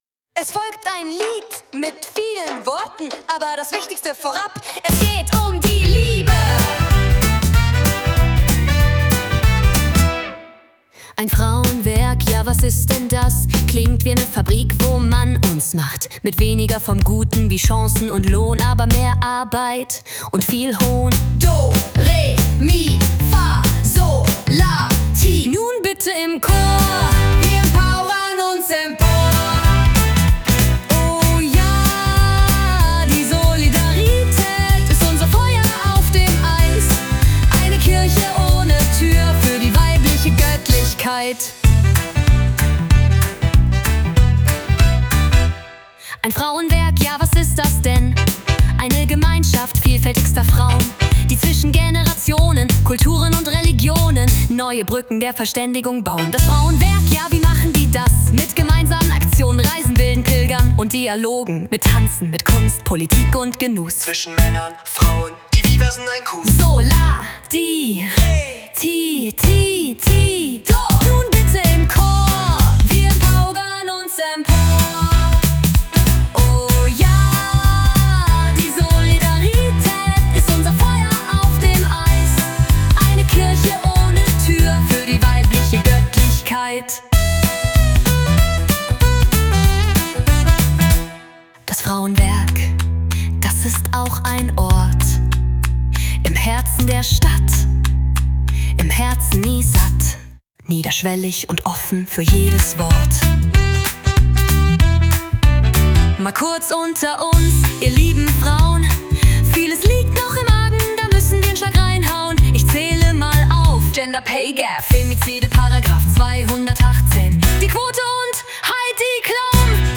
Es-folgt-ein-Lied-mit-vielen-Worten-2.mp3